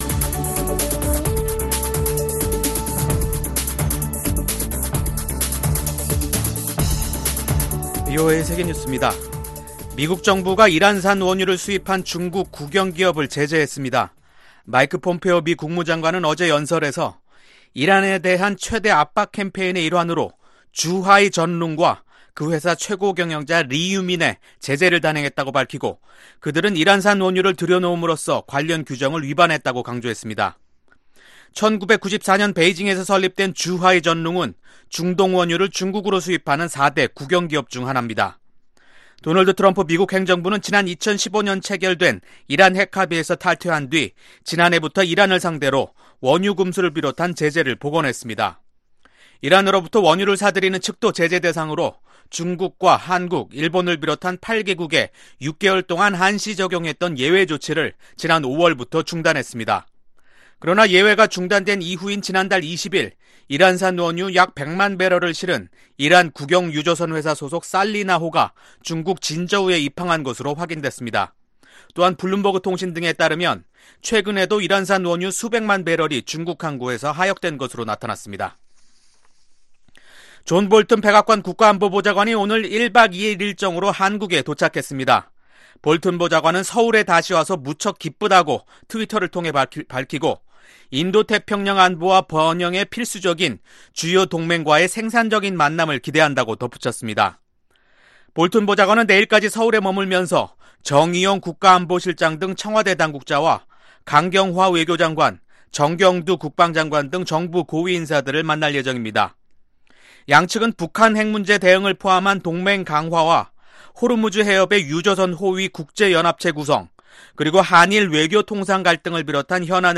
VOA 한국어 간판 뉴스 프로그램 '뉴스 투데이', 2019년 7월 23일 2부 방송입니다. 마이크 폼페오 미 국무장관은 미국과 북한이 새로운 아이디어들을 갖고 대화에 나오길 바란다고 말했습니다. 북한이 오는 8월로 예정된 ‘19-2 동맹’ 연합위기관리연습, CPX를 현실화하면 미-북 실무 협상에 영향을 주게 될 것이라고 밝혔습니다.